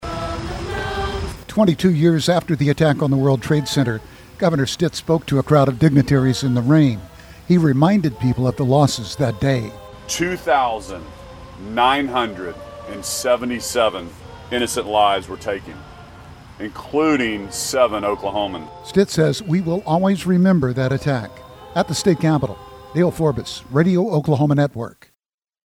Twenty-two years after the attack on the World Trade Center, Governor Stitt spoke to a crowd of dignitaries at the state's Veterans Memorial.